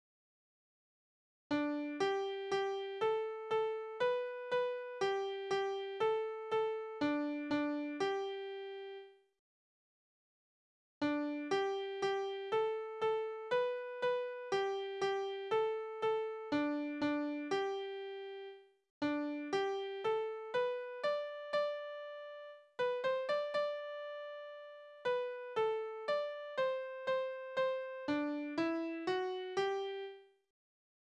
Berufslieder: Pfannenflicker
Tonart: G-Dur
Taktart: 4/4
Tonumfang: Oktave